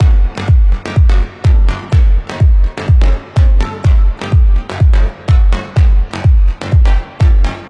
仅使用纯模拟合成器录制，为声音赋予独特的质感和温暖。 Techno、Tech House 和其他子类型的完美合成器线。
支持试听： 是 试听格式/比特率： AAC 40kbps Play Pause Demo1 选择曲目,缓冲完成点play播放 注： 试听素材音质经过压缩处理,原素材未作任何修改。
BFMMTSSynthLoop09Gbm125bpm.m4a